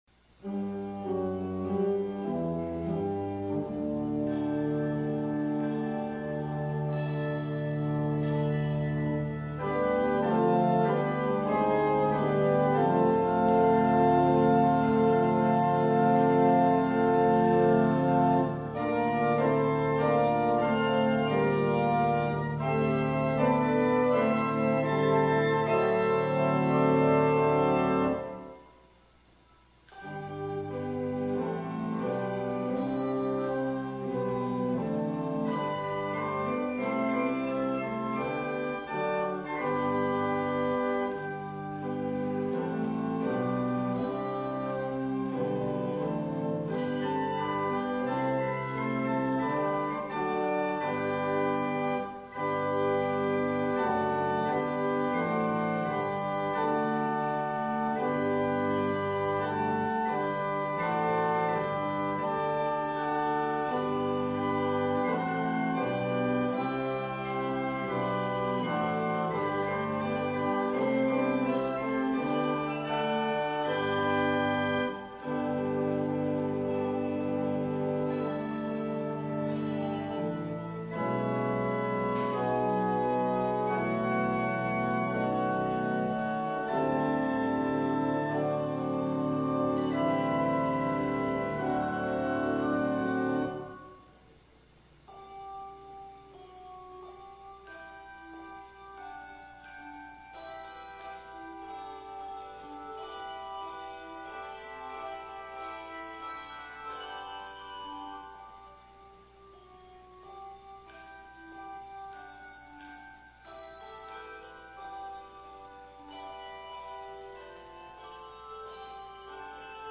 Handbell Music